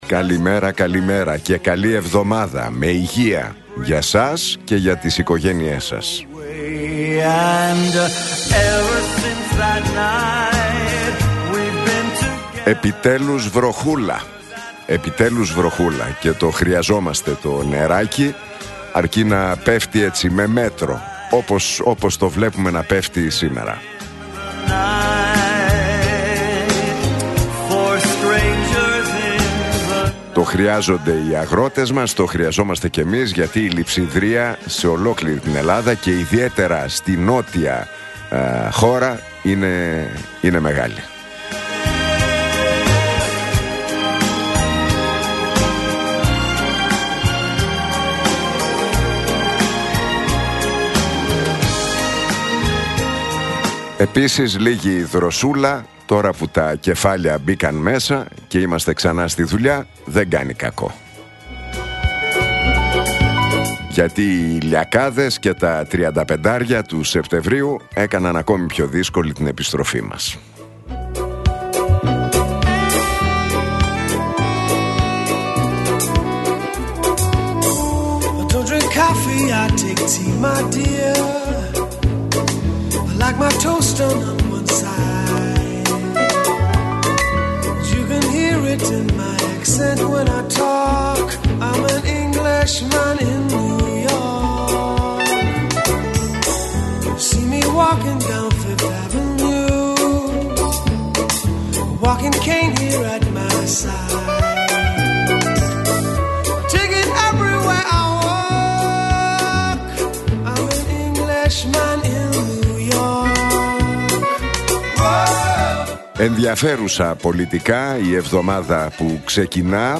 Ακούστε το σχόλιο του Νίκου Χατζηνικολάου στον ραδιοφωνικό σταθμό Realfm 97,8, την Δευτέρα 29 Σεπτεμβρίου 2025.